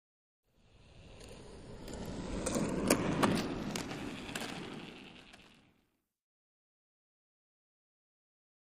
Roller Skating; Skating By.